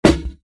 DHL SNARE 2.mp3